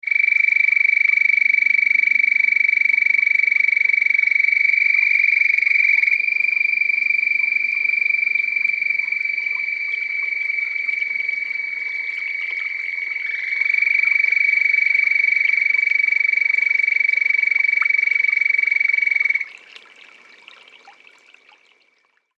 The call of the Red-spotted Toad is a prolonged loud high-pitched musical trill, lasting up to 10 seconds, which is produced at night .
Sound This is a 23 second recording of two close toads calling at night from isolated pools in a canyon bottom in Yavapai County, Arizona (shown on the right.)